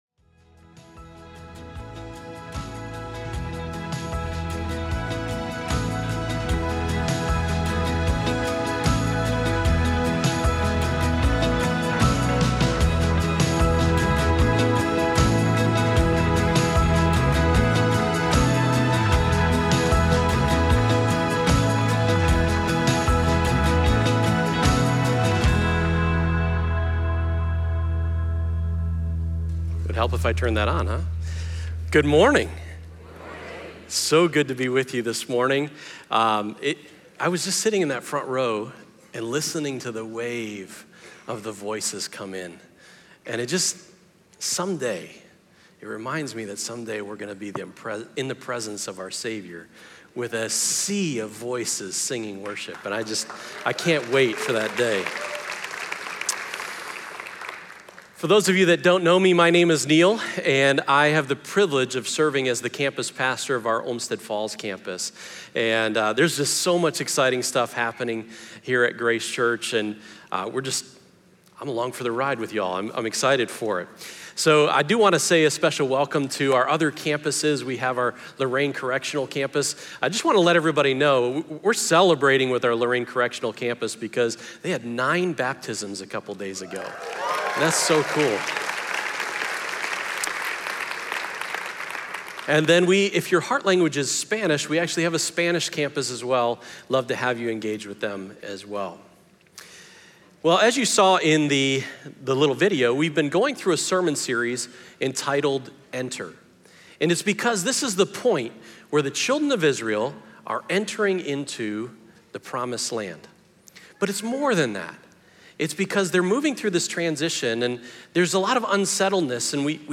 In this message from our Enter series, we look at Joshua 4, where God called the Israelites to stop and mark the moment He led them across the Jordan River.